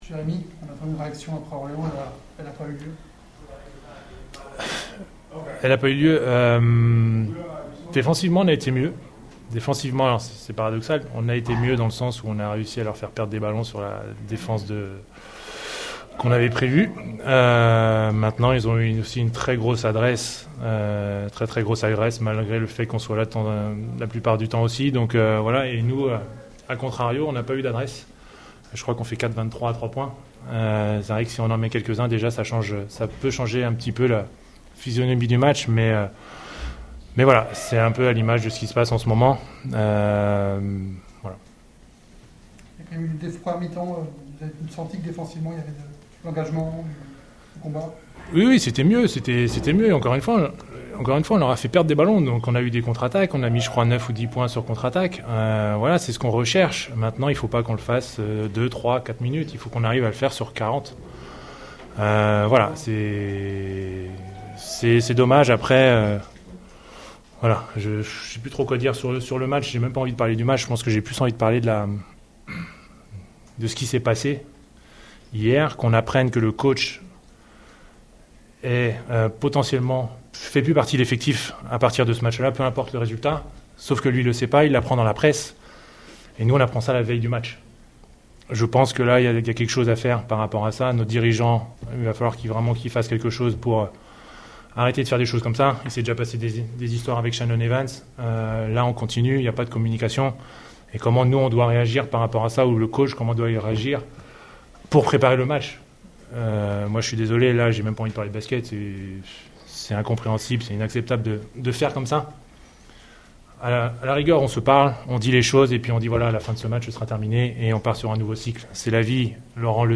LES Réactions